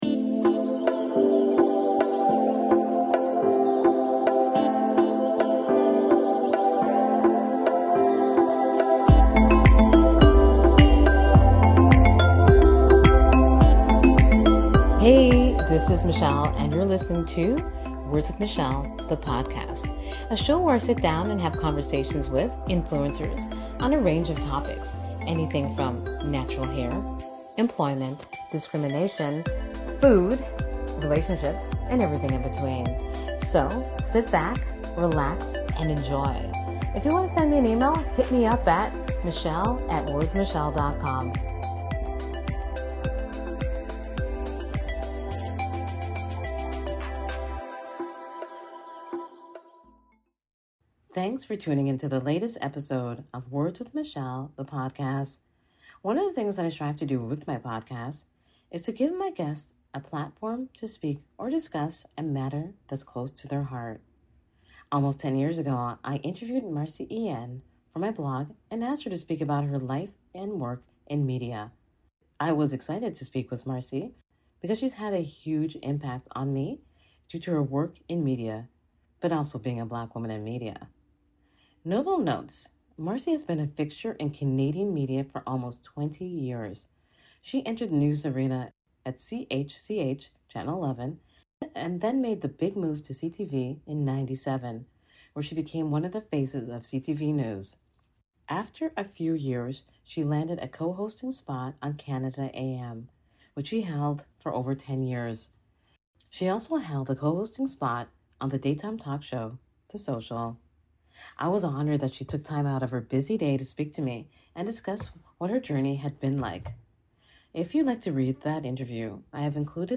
I sit down with Minister Marci Ien and she speaks about the 16 days of activism - with a focus on ending gender-based violence.